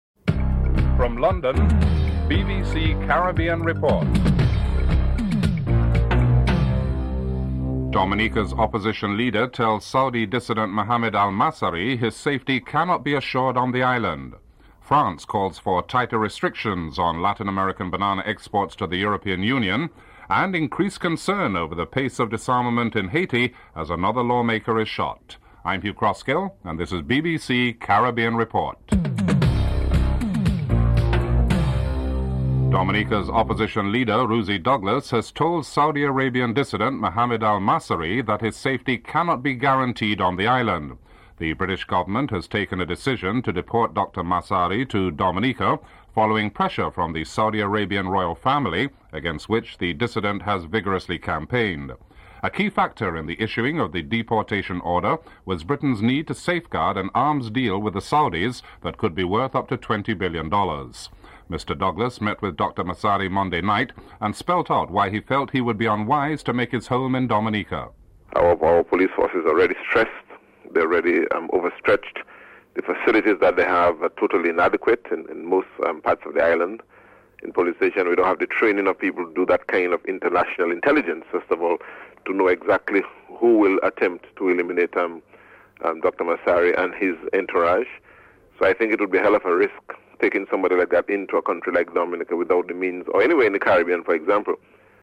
1. Headlines (00:00-00:31)
2. Dominca's Opposition leader tells Saudi dissident Mohammed Al-Massari his safety cannot be assured on the island. Opposition Leader Rosie Douglas is interviewed (00:32-04:28)